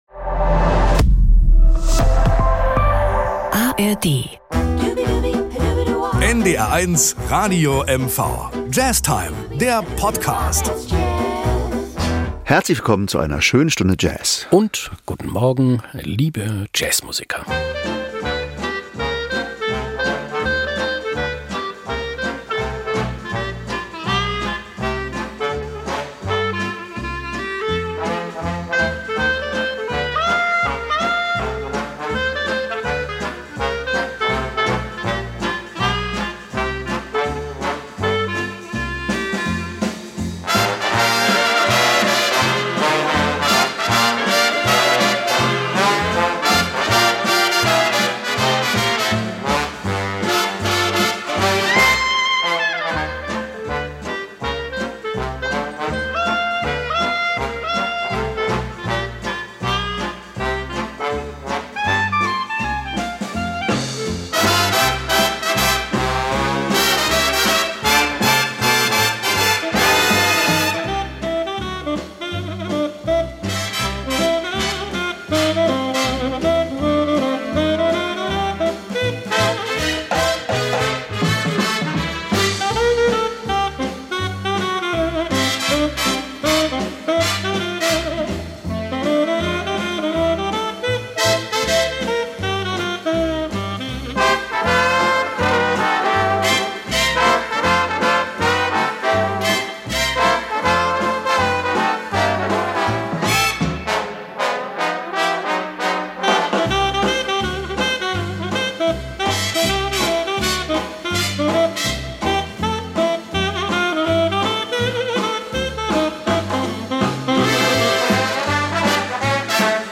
Das LIVE- Anspiel ist diesmal: